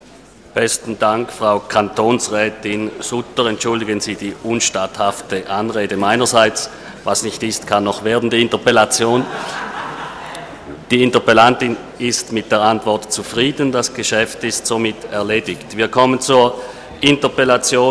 25.9.2012Wortmeldung
Session des Kantonsrates vom 24. und 25. September 2012